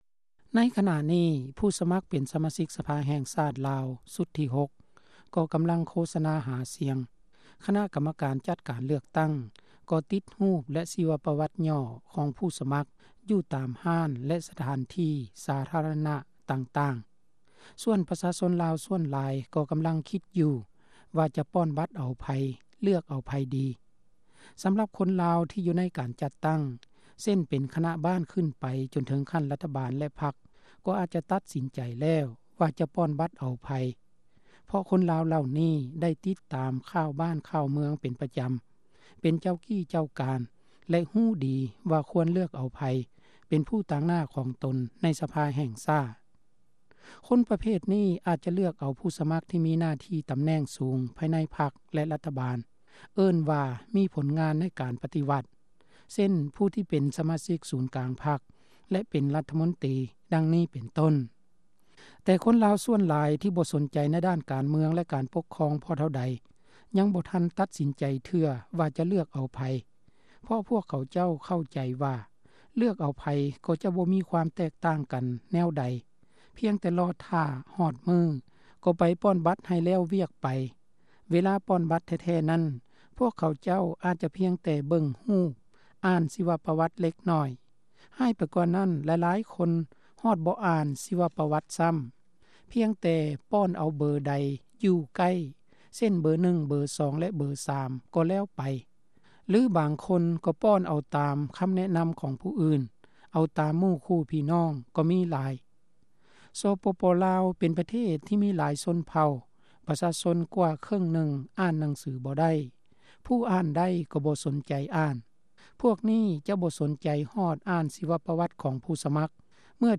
ສ່ວນນຶ່ງ ກໍຕັດສິນໃຈແລ້ວວ່າ ຈະເລືອກເອົາໃຜ, ແຕ່ສ່ວນຫລາຍບໍ່ສົນໃຈ ໃນການເລືອກຕັ້ງ ພໍເທົ່າໃດ. ຣາຍງານ  ໂດຍ